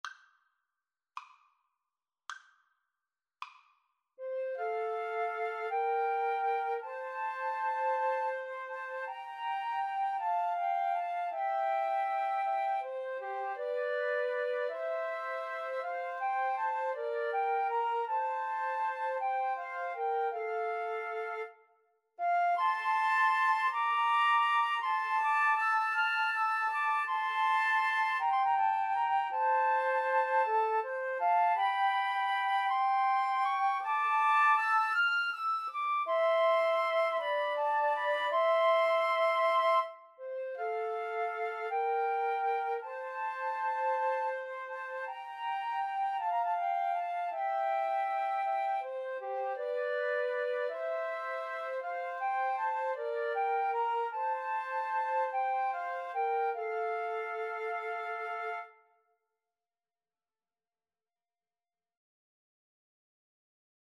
Andante
6/8 (View more 6/8 Music)
Flute Trio  (View more Intermediate Flute Trio Music)